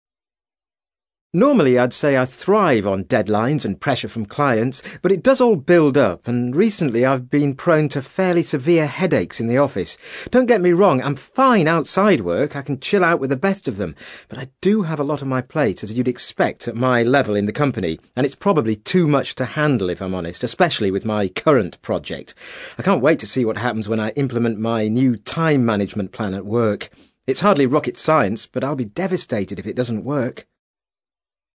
ACTIVITY 162: You will hear five short extracts in which five people are talking about problems related to their work.